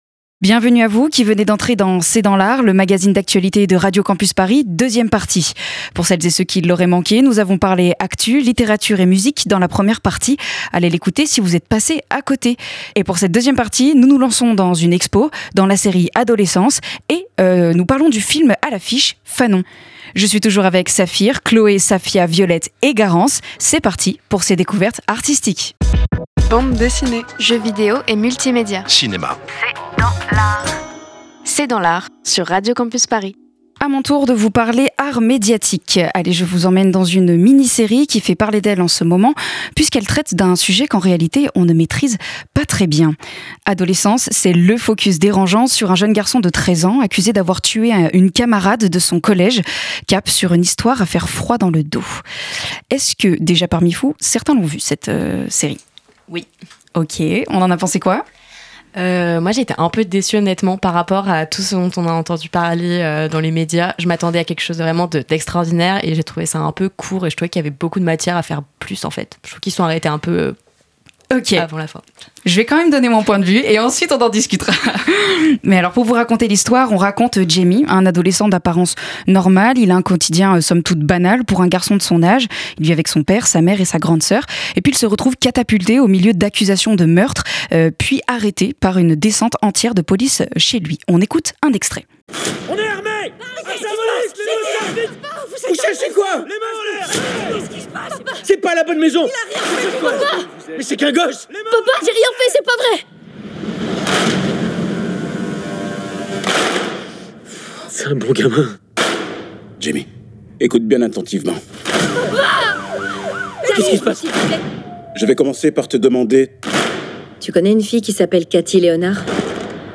C’est dans l’art, c’est l’émission d'actualité culturelle de Radio Campus Paris.